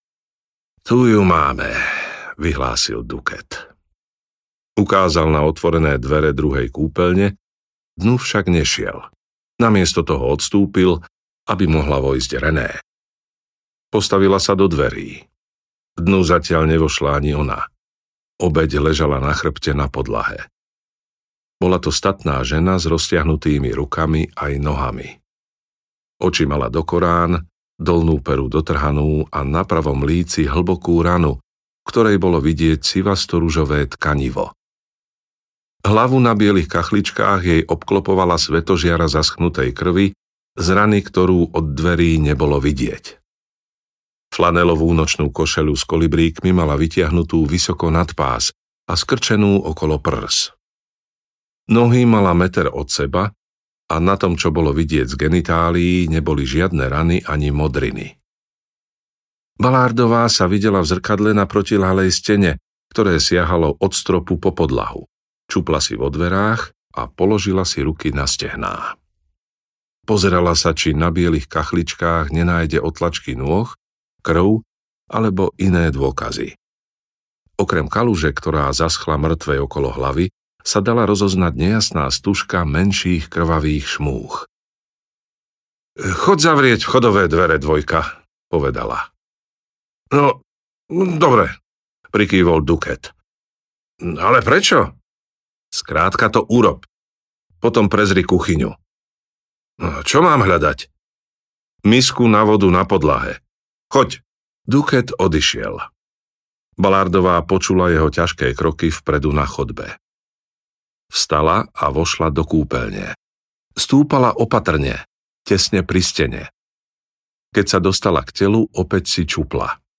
Tichá noc audiokniha
Ukázka z knihy